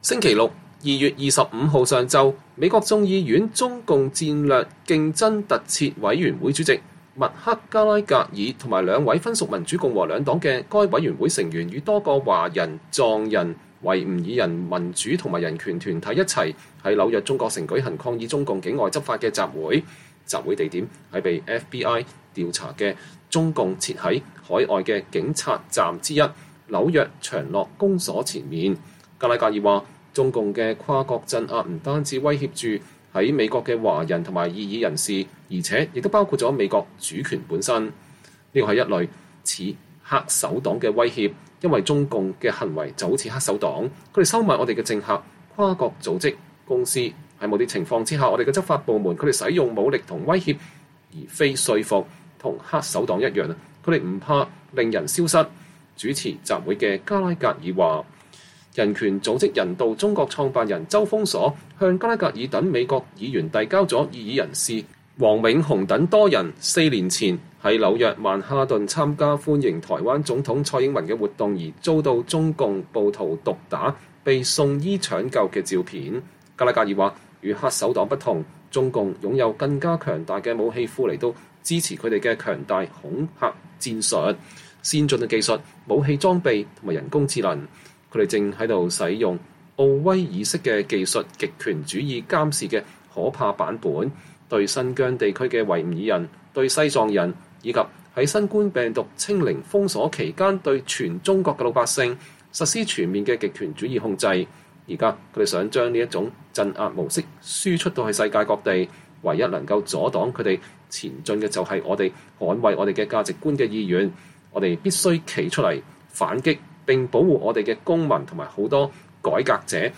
美國眾院與中共戰略競爭特設委員會主席麥克·加拉格爾（Rep. Mike Gallagher, R-WI）和兩位分屬民主共和兩黨的該委員會成員，與多個華人、藏人、維吾爾人民主和人權團體一起，在紐約中國城舉行抗議中共境外執法的集會。
兩位分屬民主共和兩黨的委員會成員參加了集會並發言。